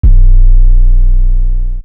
Sizzle808.wav